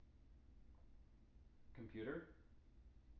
wake-word
tng-computer-91.wav